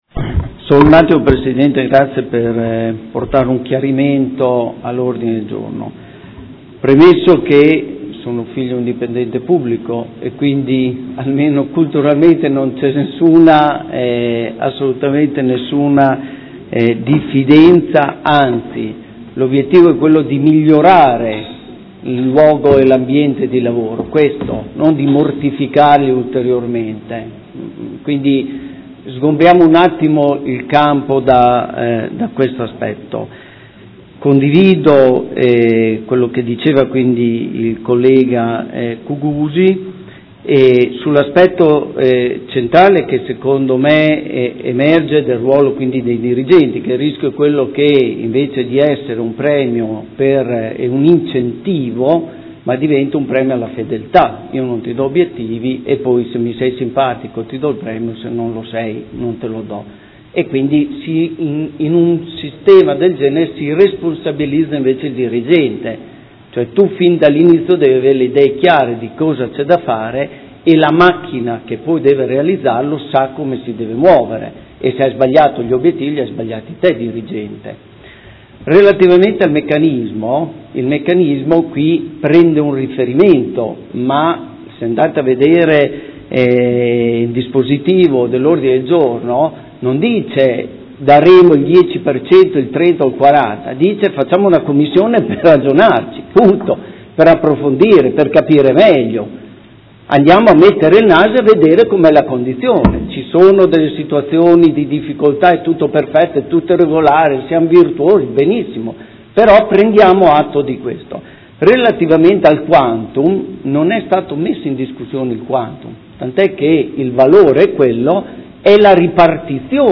Seduta del 14/04/2016 Dibattito. Ordine del Giorno presentato dal Consigliere Montanini del Gruppo Consiliare CambiAMOdena avente per oggetto: Criteri di erogazione della parte variabile dello stipendio dei dipendenti comunali